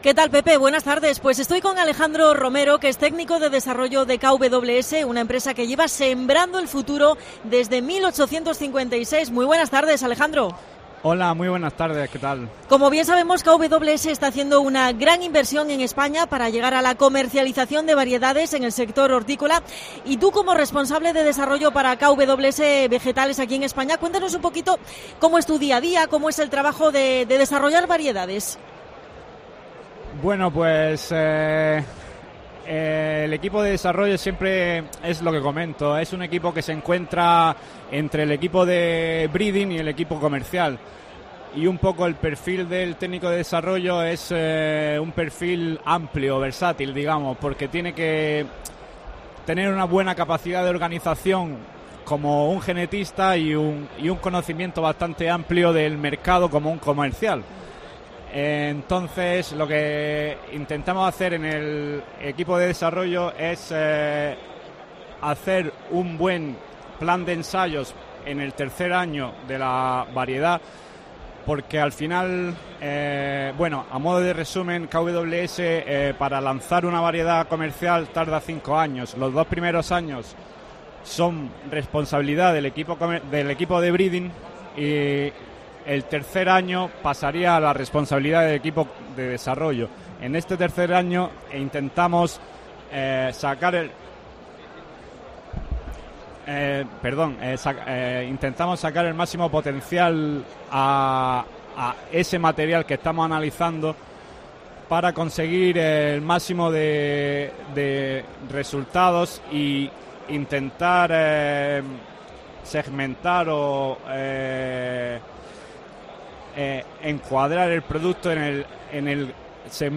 AUDIO: Especial COPE Almería desde ExpoLevante en Níjar.